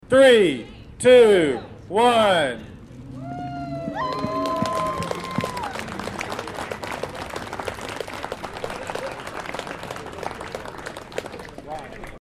0504-Aggieville-applause.mp3